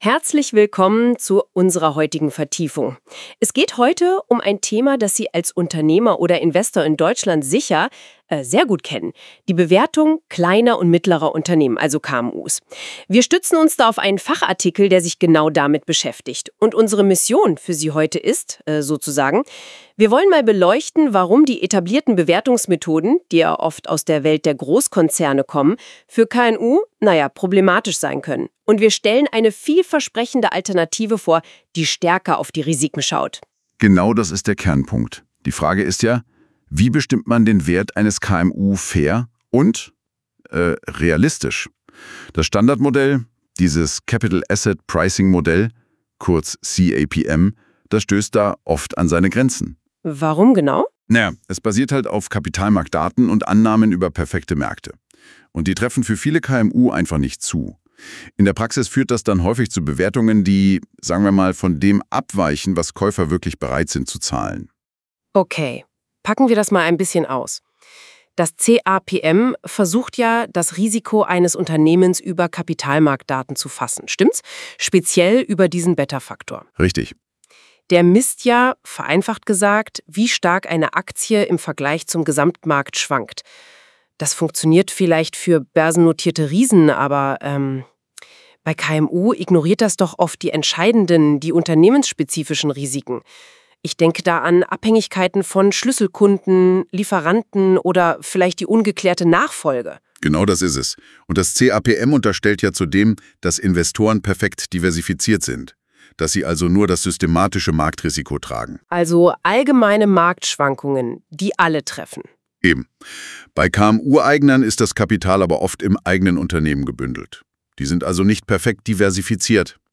• Die vorliegende Audiodatei wurde unter zu Hilfenahme von künstlicher Intelligenz erstellt.